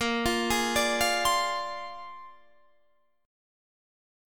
A#9 Chord